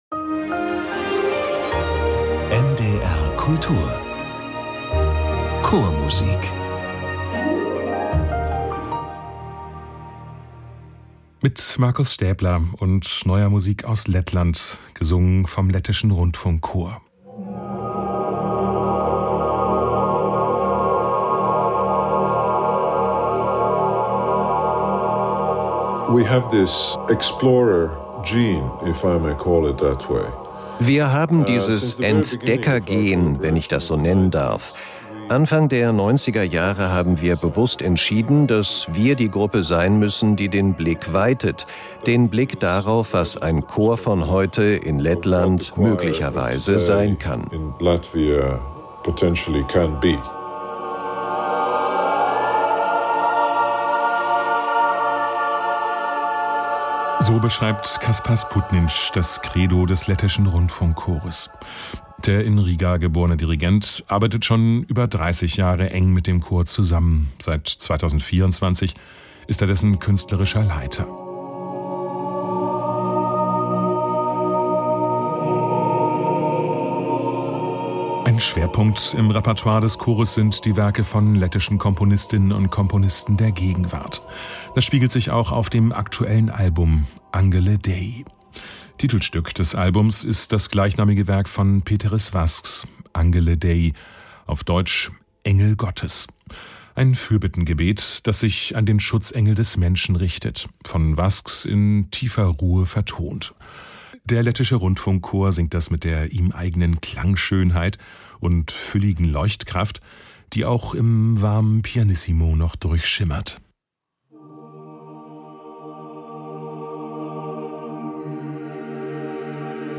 Der Lettische Rundfunkchor fasziniert mit Werken von lettischen Komponistinnen und Komponisten der Gegenwart.